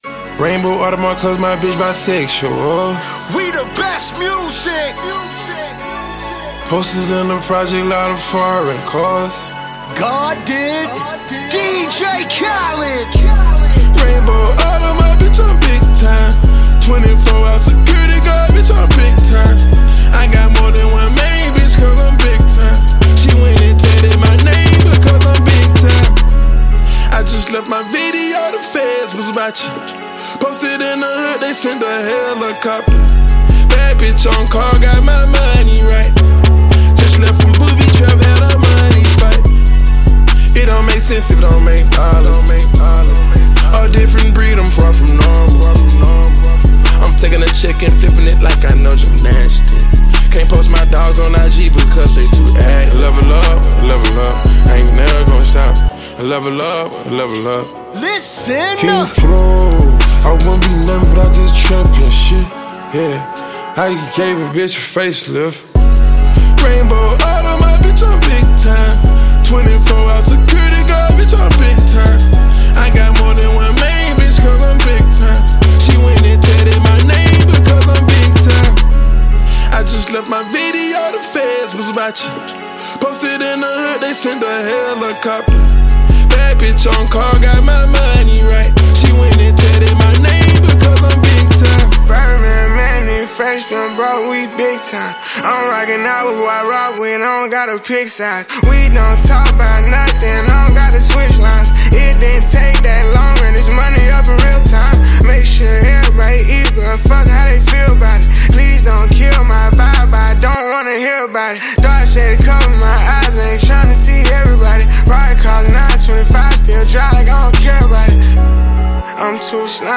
Each song has a strong beat and powerful lyrics.